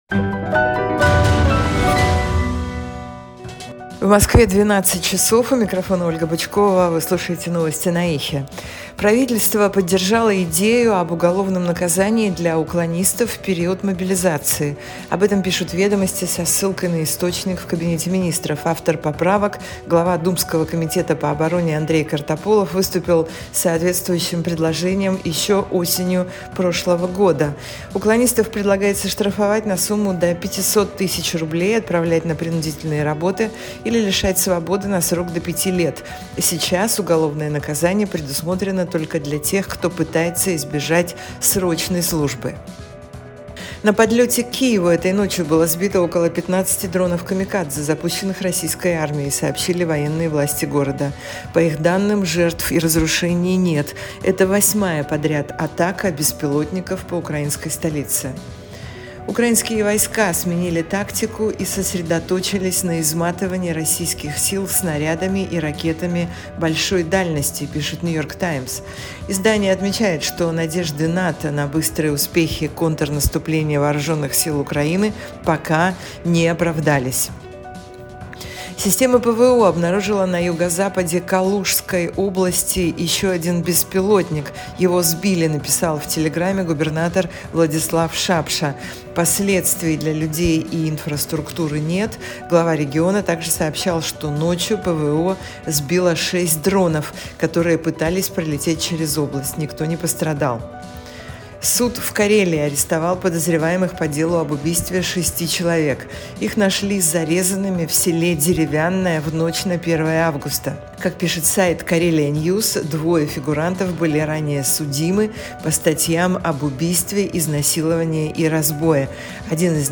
Новости